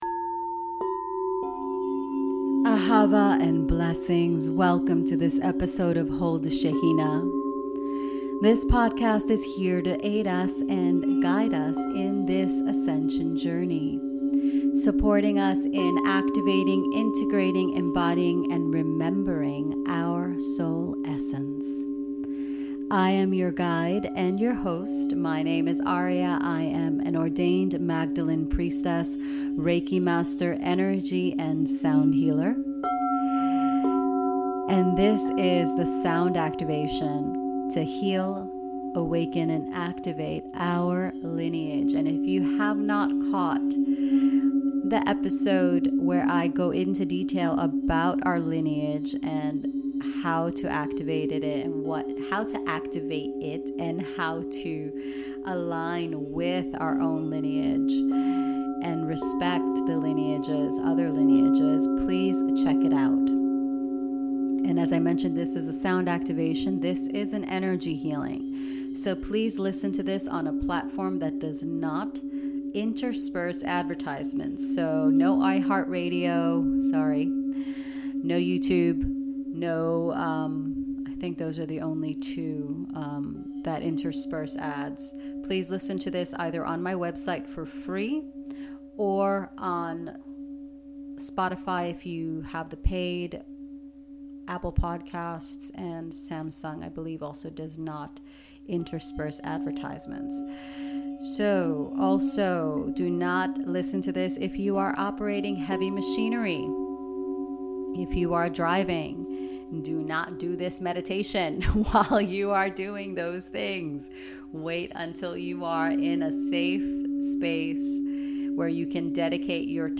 Join me for this gentle, powerful and transformative Sound Activation to heal and receive the wisdom of our lineage.